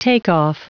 Prononciation du mot takeoff en anglais (fichier audio)
Prononciation du mot : takeoff